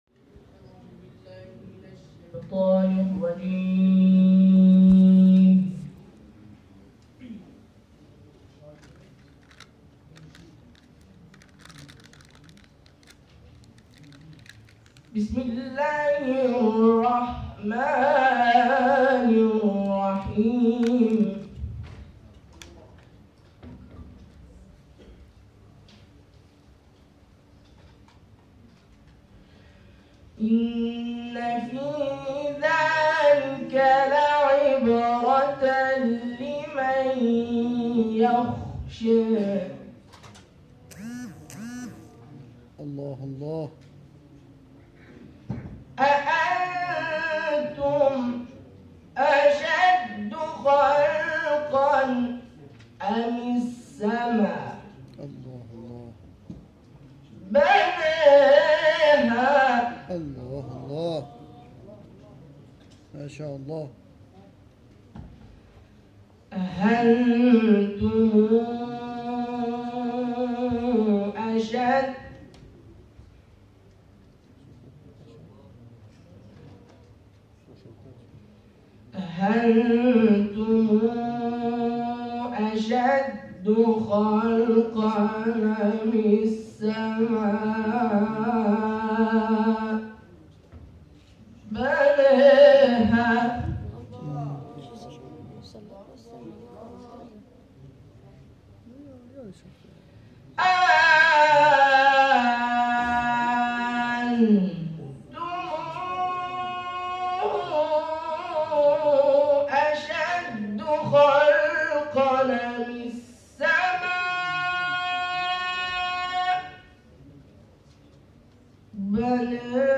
گروه شبکه اجتماعی: فایل صوتی تلاوت کاروان اعزامی نخبگان آموزشگاه قرآنی صراط به دومین دوره مسابقات شوق تلاوت کشور در کربلای معلی ارائه می‌شود.